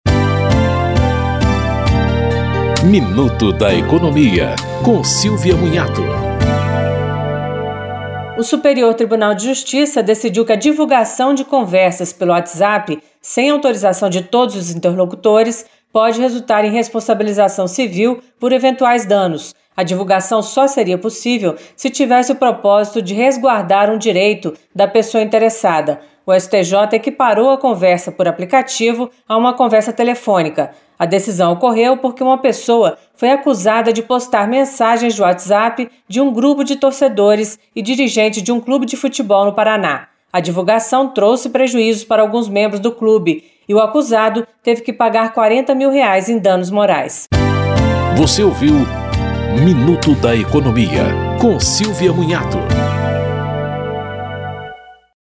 Apresentação